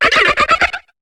Cri de Mustébouée dans Pokémon HOME.